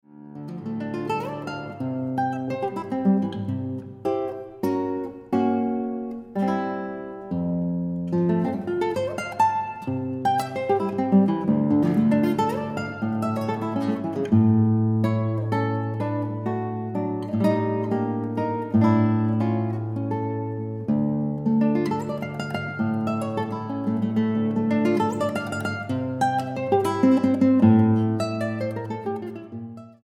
guitarra.
Moderato